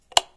switch30.wav